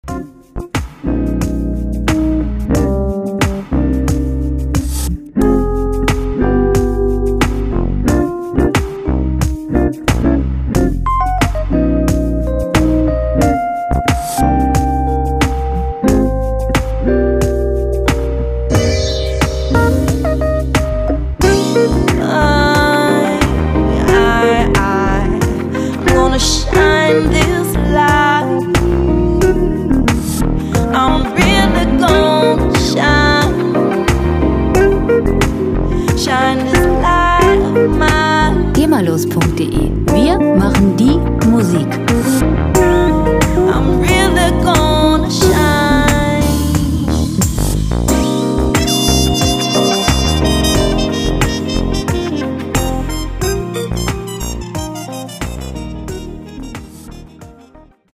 Lounge Musik - Cool und lässig
Musikstil: Soul-Jazz
Tempo: 90 bpm
Tonart: C-Moll
Charakter: jazzig, sanft